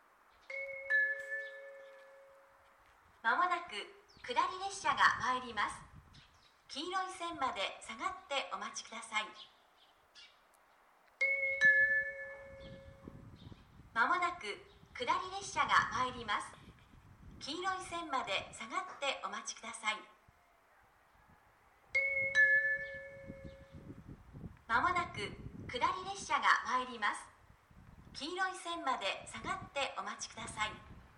この駅では接近放送が設置されています。
接近放送普通　秋田行き接近放送です。